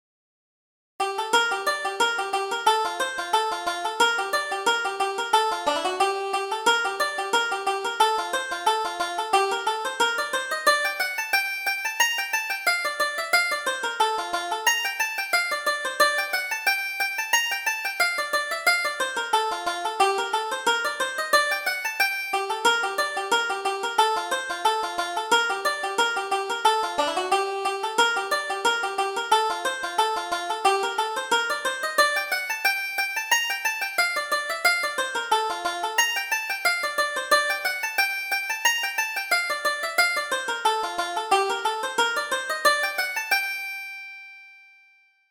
Reel: Johnny's Wedding